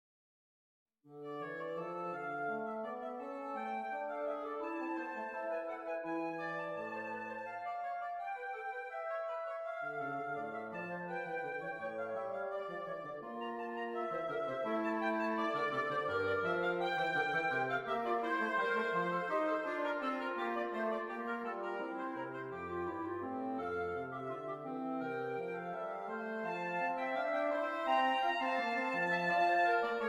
Woodwind Trio: Oboe, Clarinet in Bb, Bassoon
Does Not Contain Lyrics
D Minor
Moderato